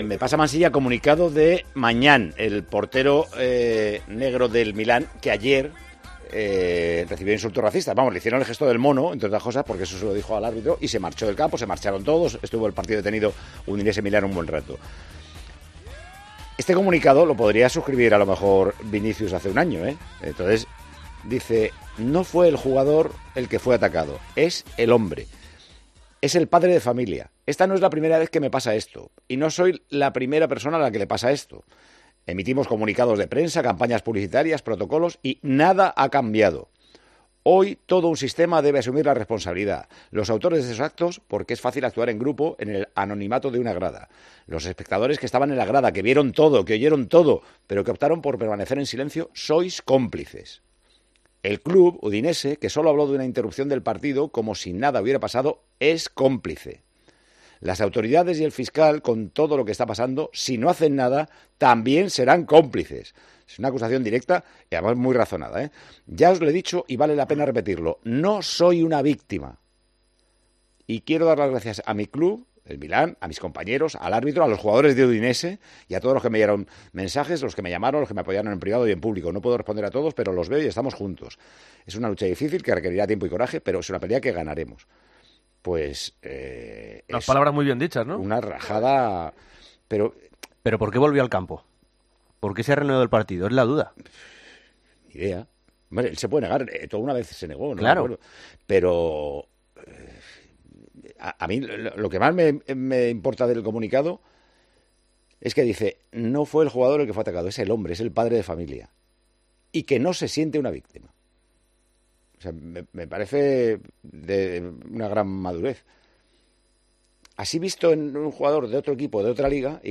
El director de Tiempo de Juego ha condenado los hechos tras leer el comunicado del portero francés en antena: "Es una acusación directa y, además, muy razonada", aseguró sobre lo publicado por Maignan.